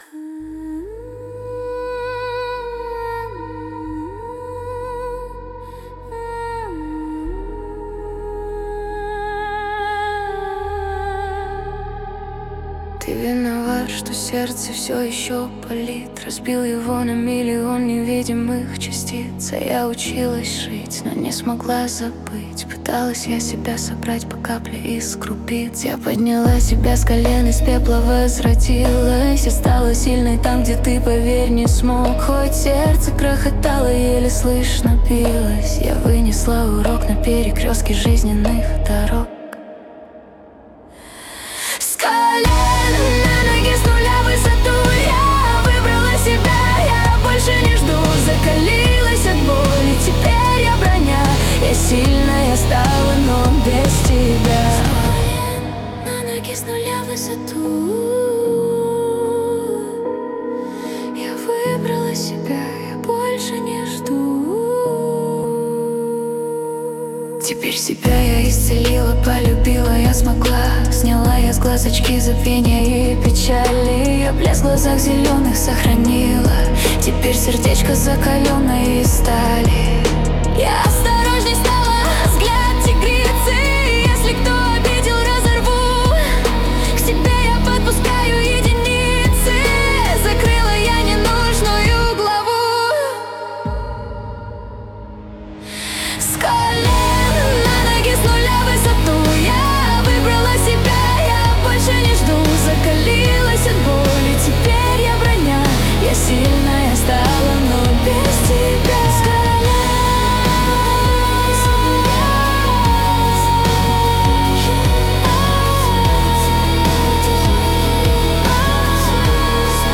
Качество: 320 kbps, stereo
Поп музыка, Русские поп песни